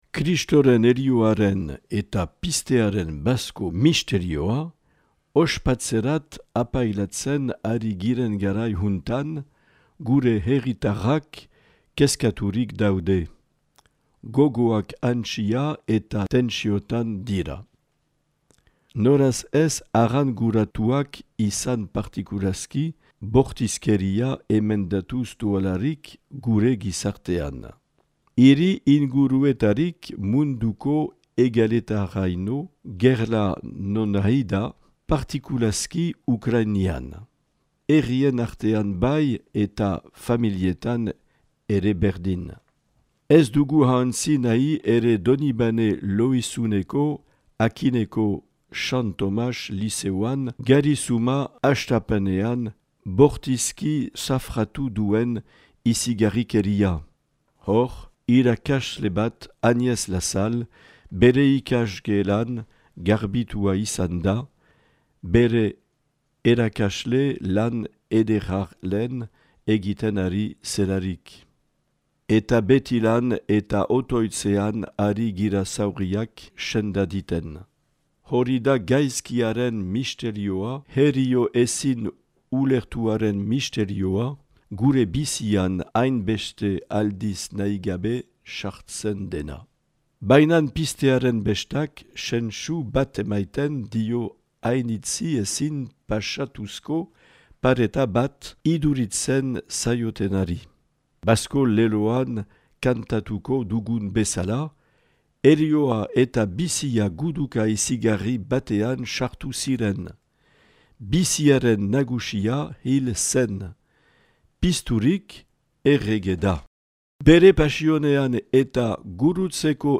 Bazko mezua 2023 - Marc Aillet, Baionako apezpikua